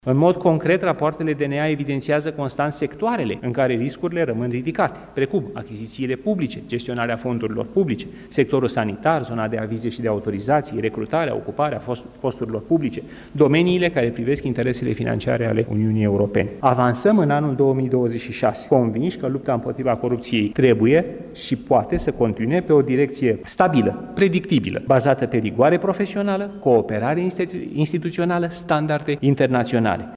Aflat la prezentarea raportului Direcției Naționale Anticorupție pe anul 2025, oficialul a motivat – cu statistici – că activitatea procurorilor a devenit mai eficientă.